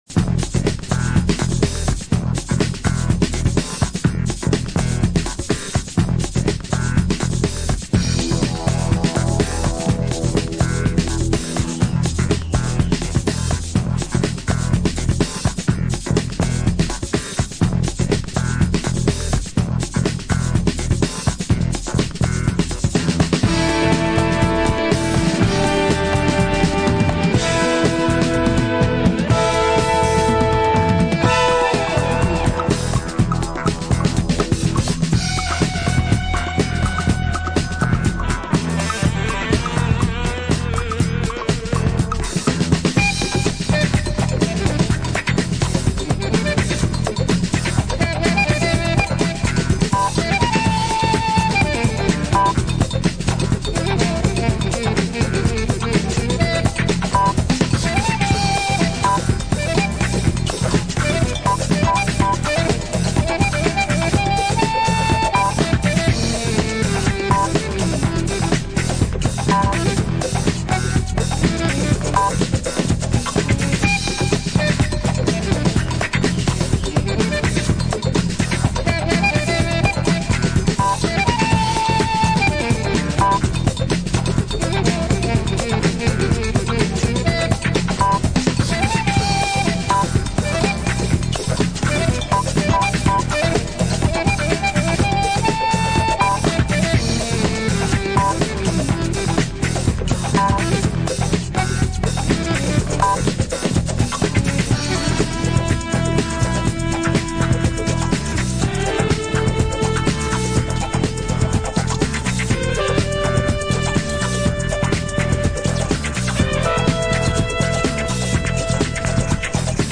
BPM120のハウス・テンポで展開するオーセンティックなアフロ・キューバン・ファンクB面